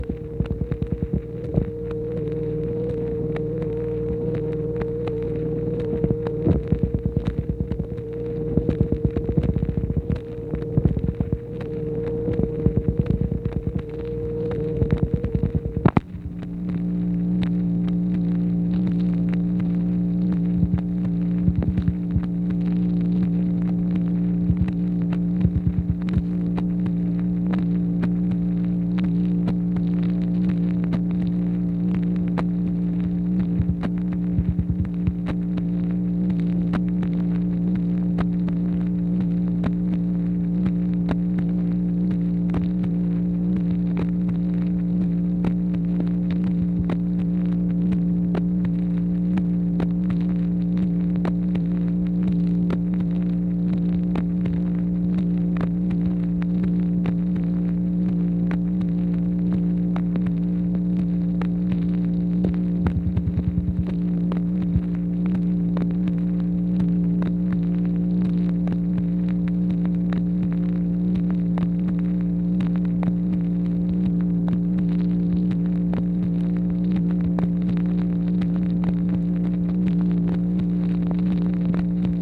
MACHINE NOISE, June 29, 1964
Secret White House Tapes